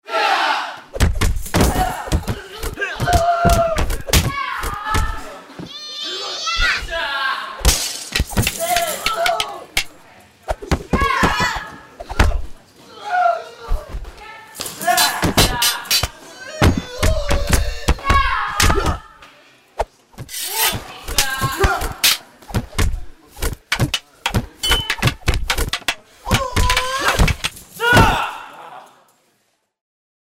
好莱坞音效:武术打斗音效素材
特点： •99种版税免费音效 •数字录制立体声 •容量245 MB 试听：
martial-arts-and-human-impacts-sound-effects.mp3